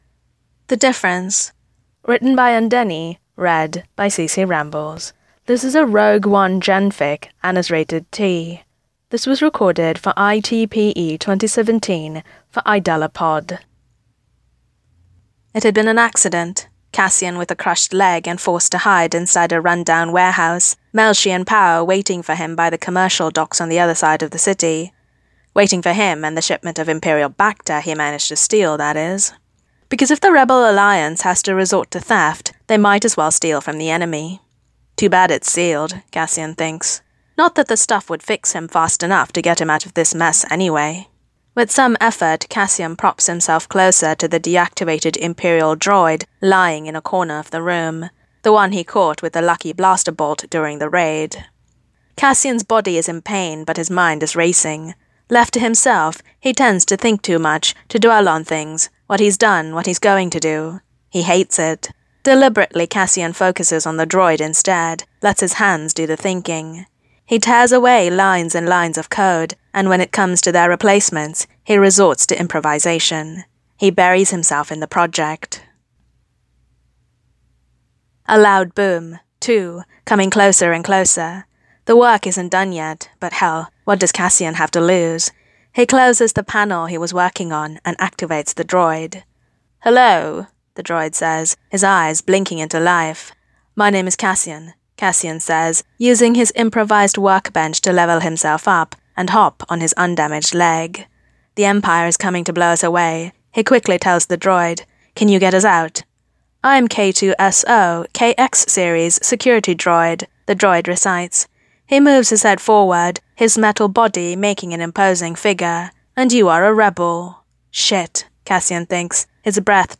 [Podfic] The Difference